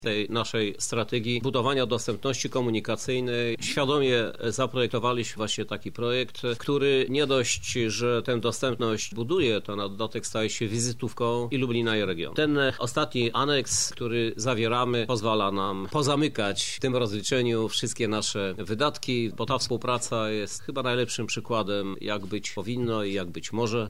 Krzysztof Żuk– mówi Prezydent Lublina, Krzysztof Żuk